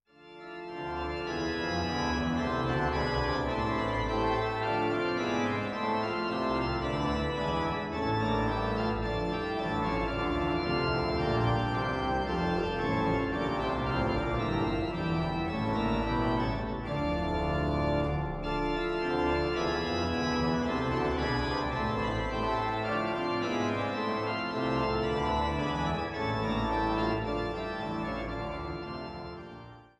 älteste Orgel im Kreis Düren